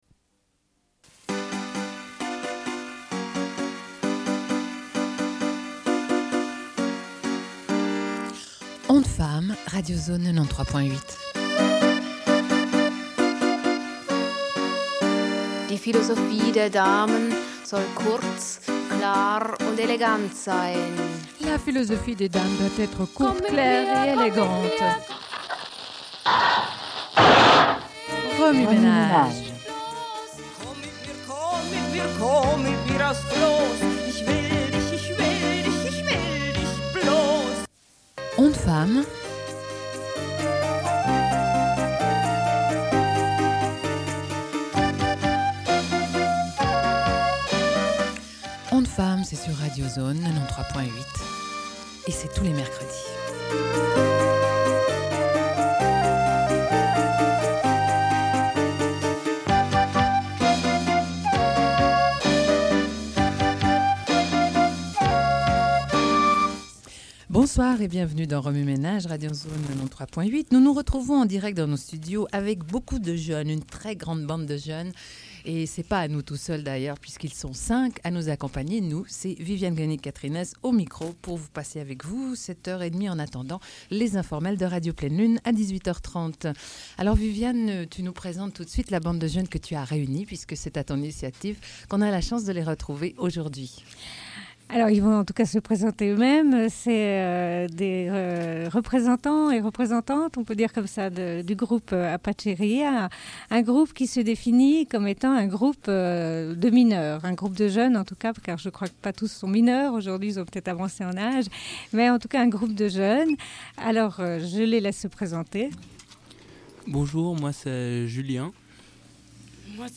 Sommaire de l'émission : en direct avec des membres du Groupe Apacheria (groupe de jeunes) et du journal Niark-niark
Une cassette audio, face A
Radio